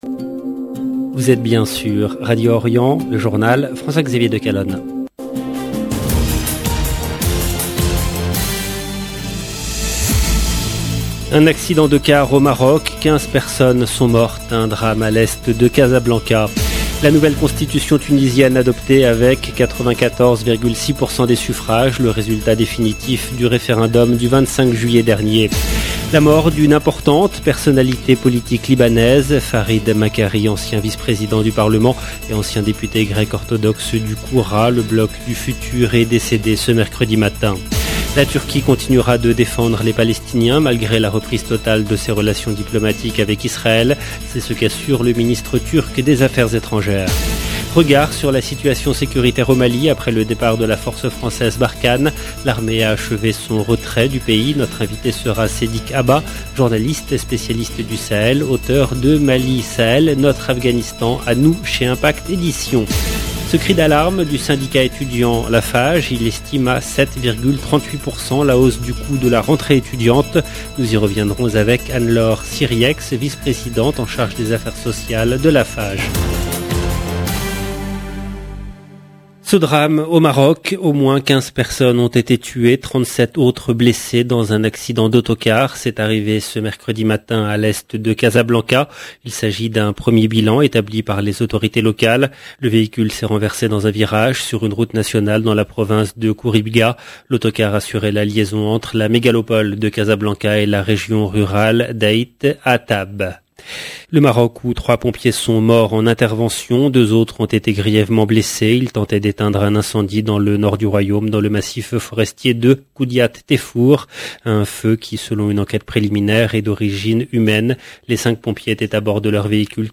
EDITION DU JOURNAL DU SOIR EN LANGUE FRANCAISE DU 17/8/2022